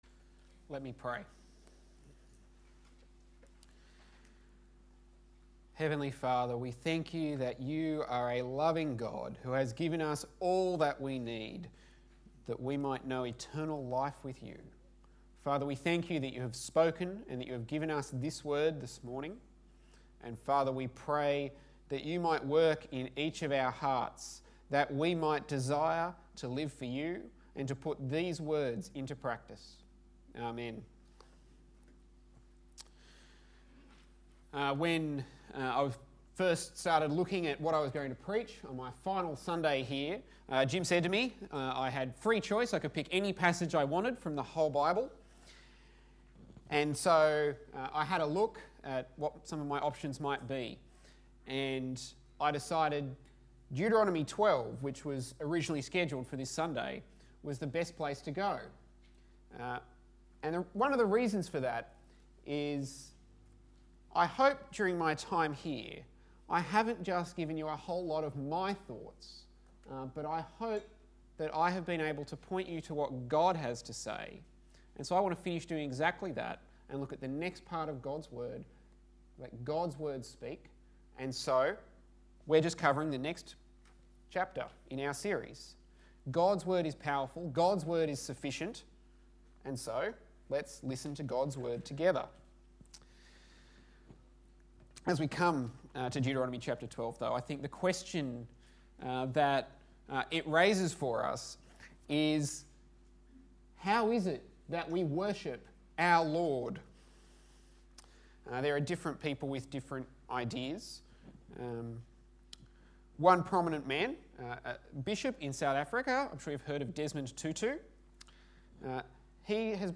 SERMON – The Joy of Worship